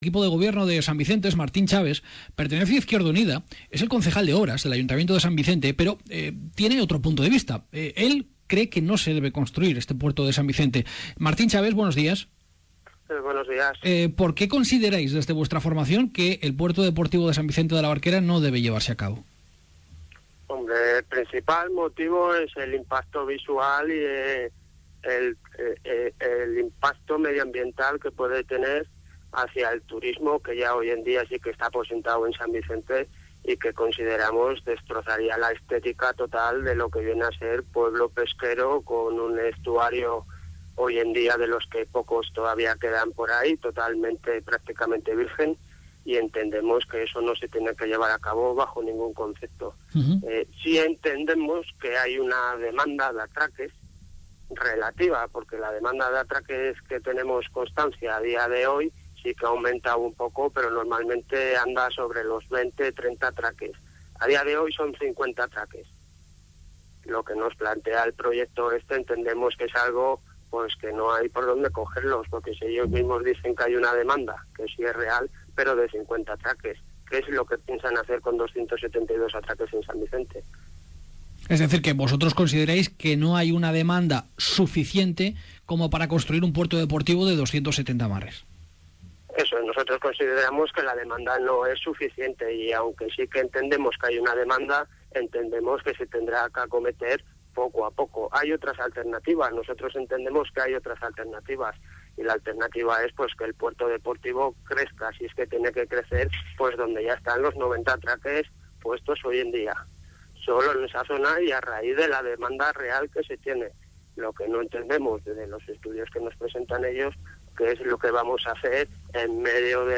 Martín Chaves, concejal de Obras en el Ayuntamiento de San Vicente de la Barquera, en Onda Cero Cantabria (91.9):